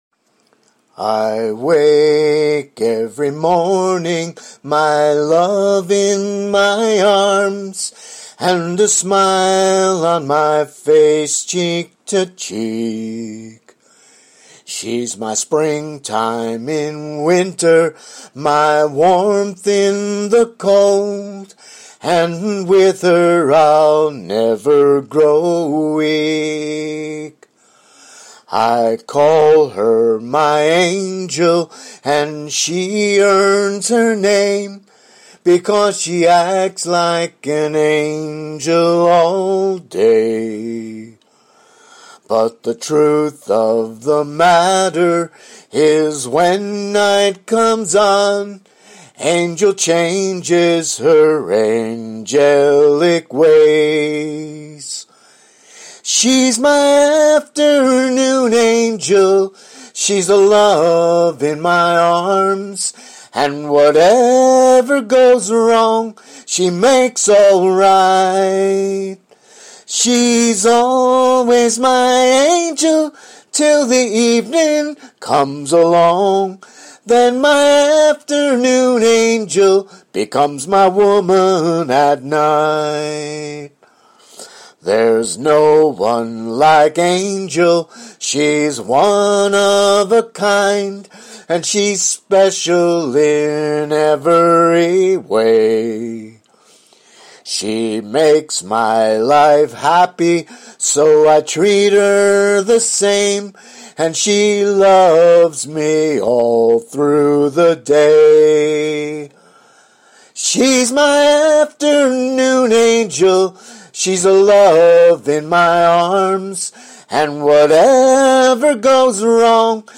5 Non-Christian, Country-Style Songs…
all are sung “a cappella” (voice only)